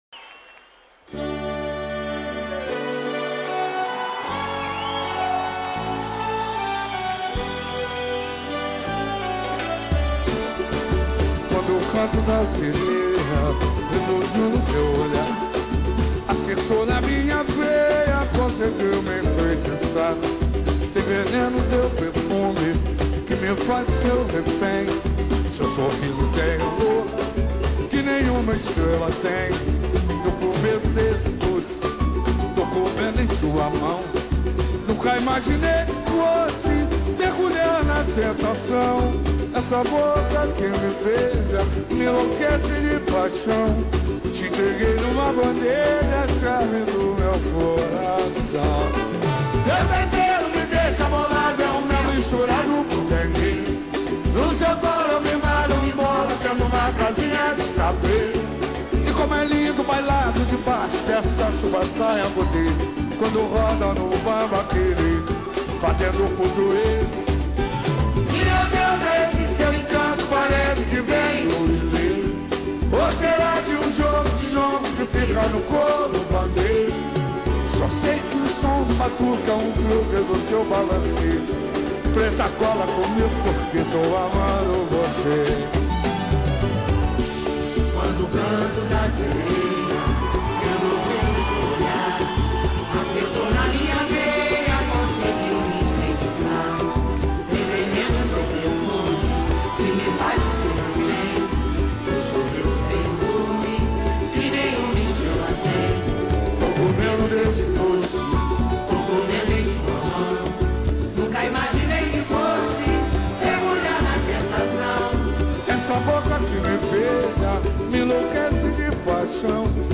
pagode